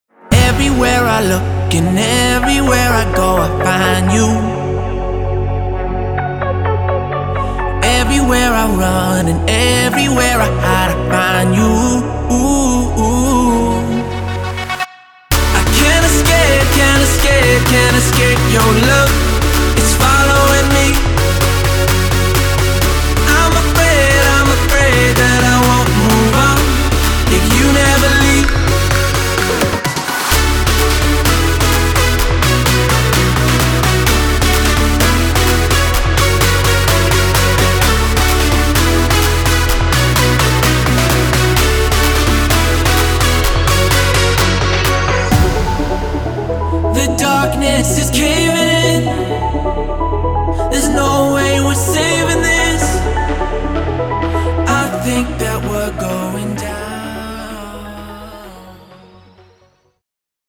captures that big festival sound!
5 construction kits, wav-loops, and lots of Serum presets